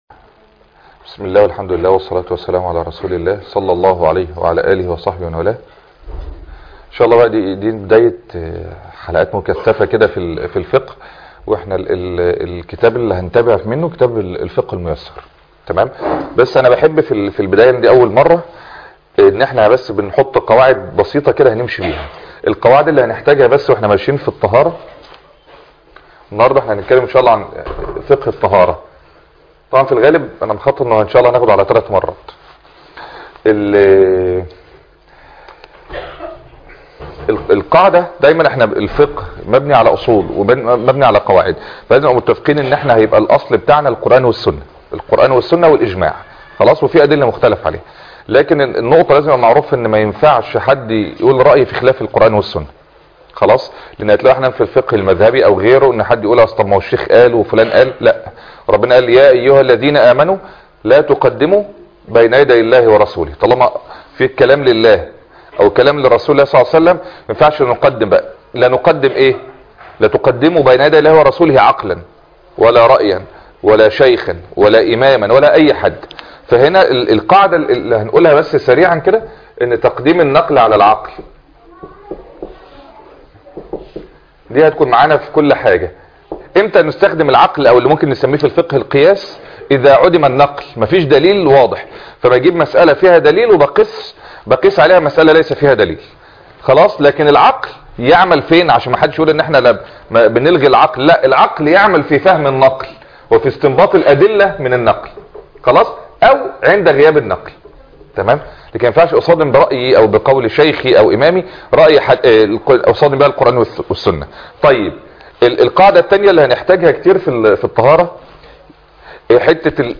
محاضرة هامة جدا ( مراجعة على فقه الطهارة ج1